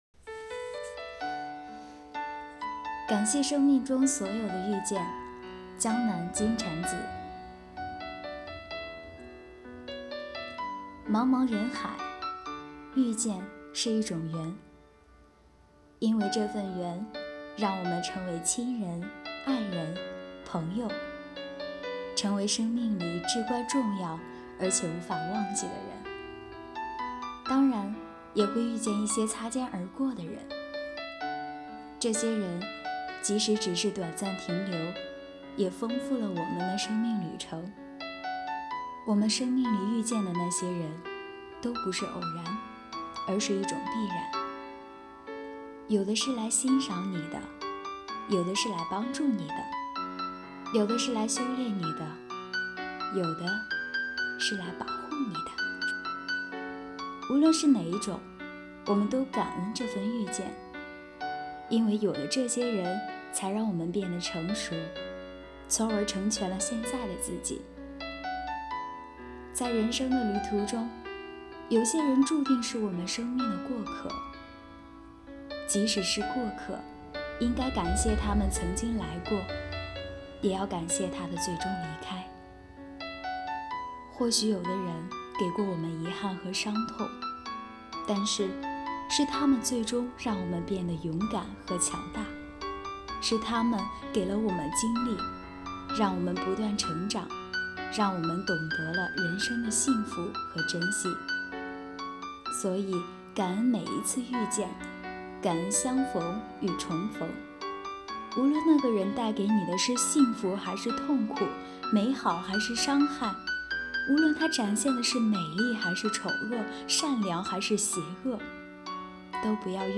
“阅读的力量 -- 读给你听”主题朗诵